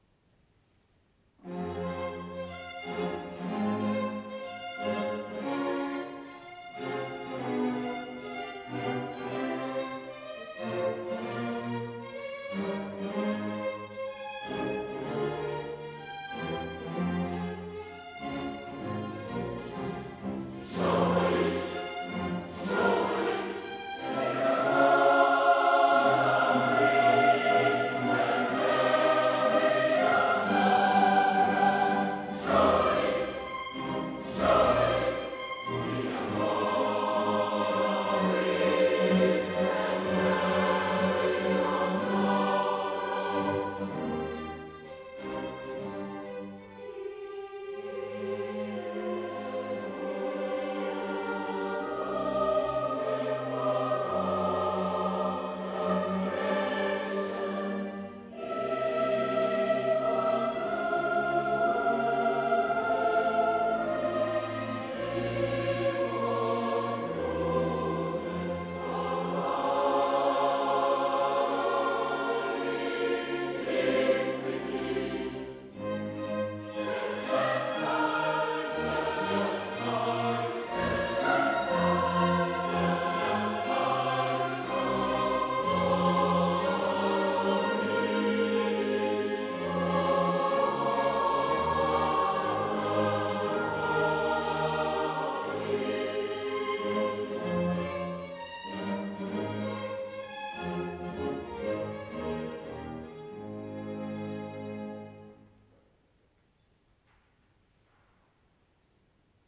22 Surely, He hath borne our griefs tiré de HAENDEL The Messiah – Concert à Aschaffenburg – 20/05/2023 par Aschaffenburger Kantorei et Choeur Saint-Germain.